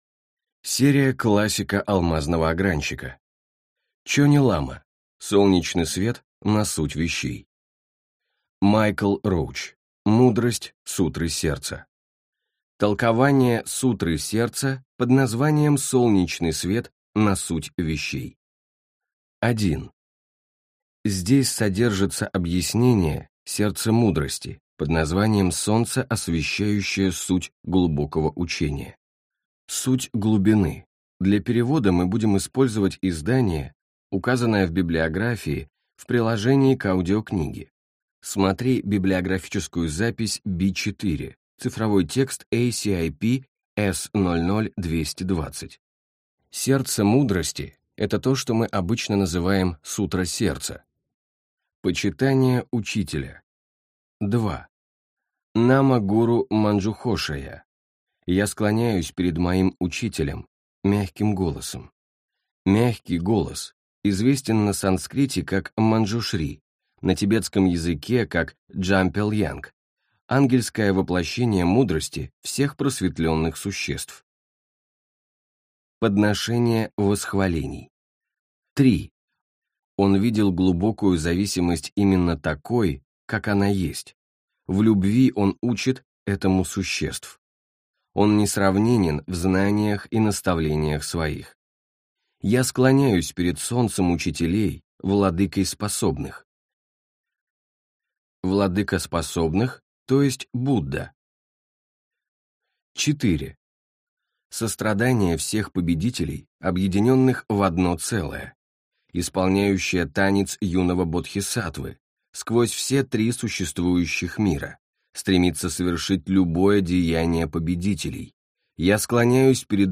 Аудиокнига Мудрость Сутры Сердца. Солнечный свет на суть вещей | Библиотека аудиокниг